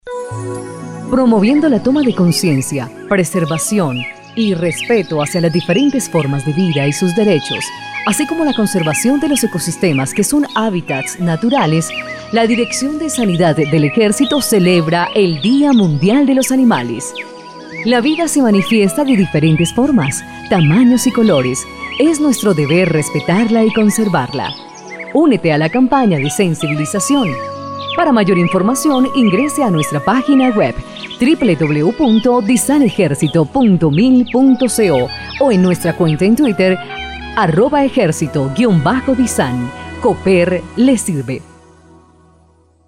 Cuña día mundial de los animales
CUNA DIA MUNDIAL DE LOS ANIMALES.mp3